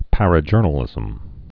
(părə-jûrnə-lĭzəm)